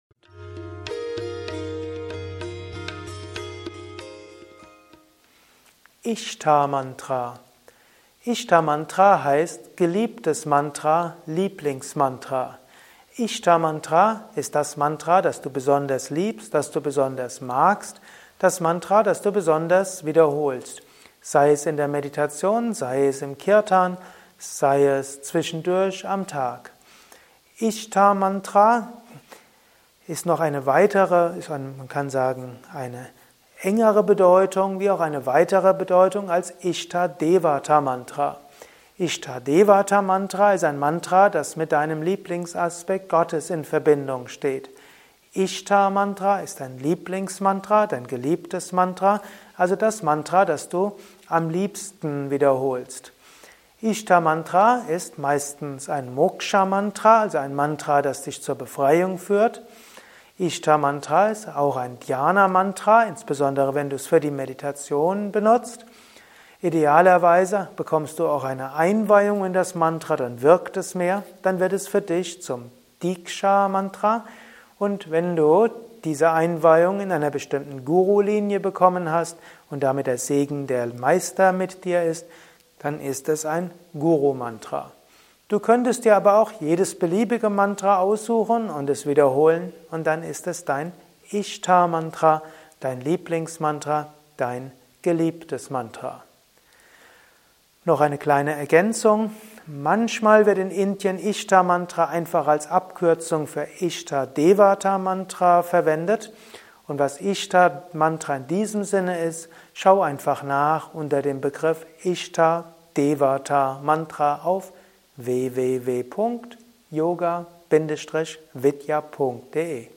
Diese Ausgabe des Mantra-Meditation-Podcast ist die Tonspur eines Mantra Videos.